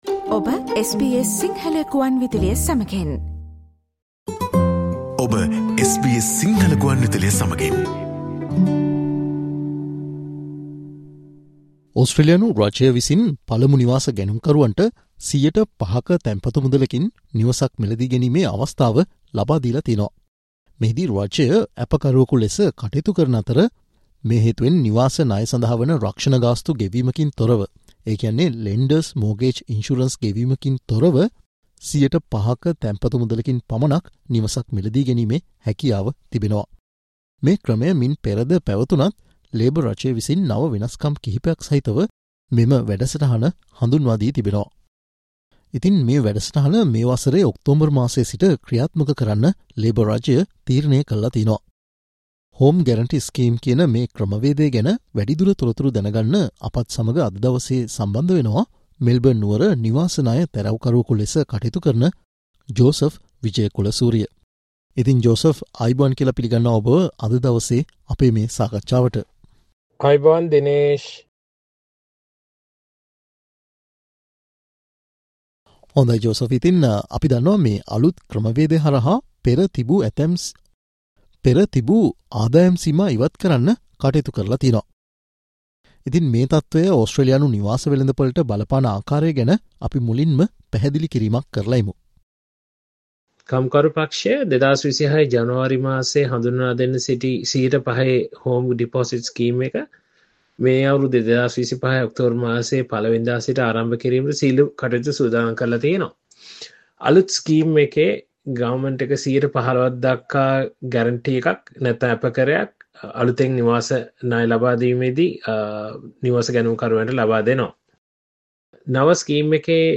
ඒ පිලිබඳ SBS සිංහල සේවය සිදුකල සාකච්චාවට සවන් දෙන්න